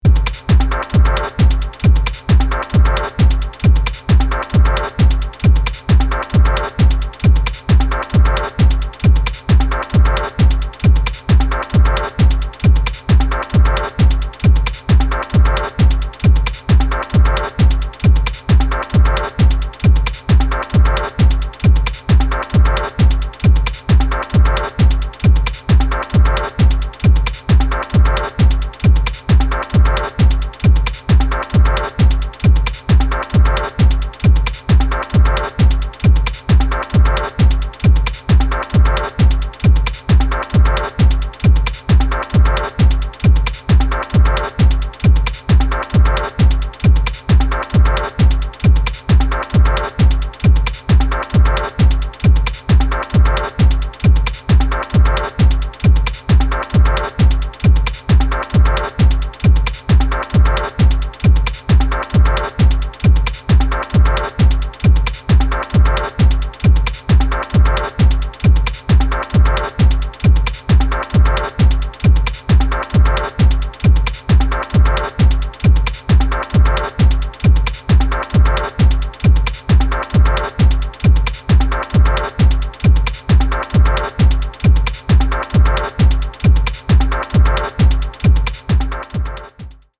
a raw yet simple and minimalist approach to house music